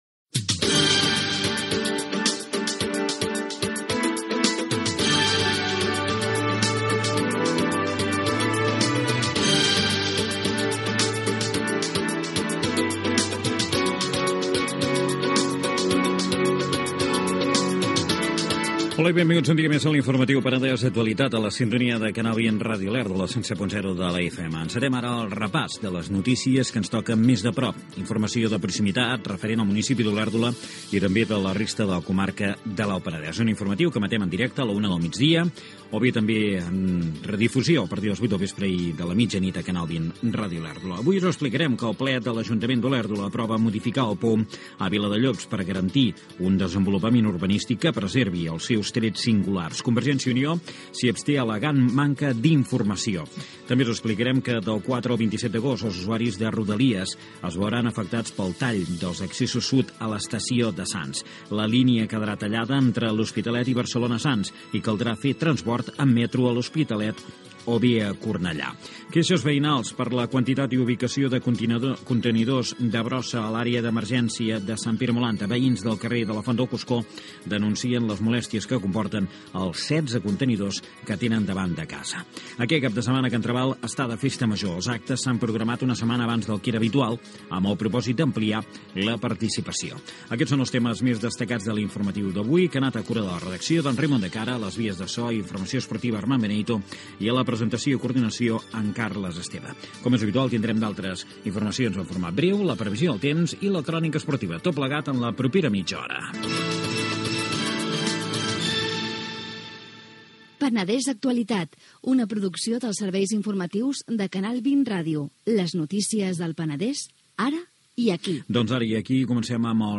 Careta, sumari, indicatiu, el ple de l'Ajuntament d'Olèrdola modifica el POM a Viladellops.
Informatiu
FM